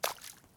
puddle2.ogg